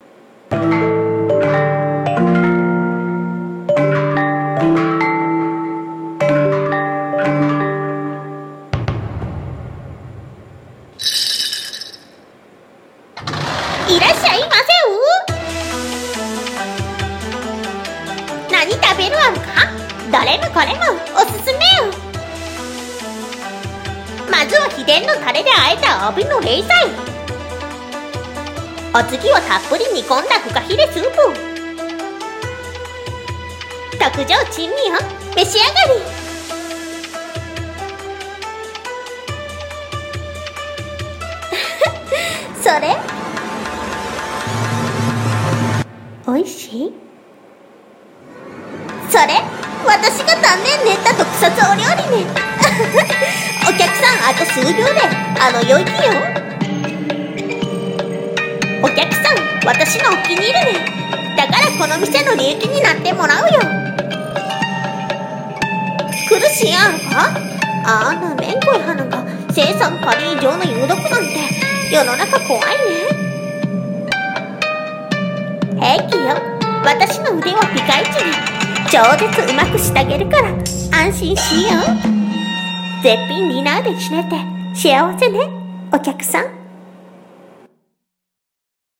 】【声劇】珍味の鈴蘭中華店 亭主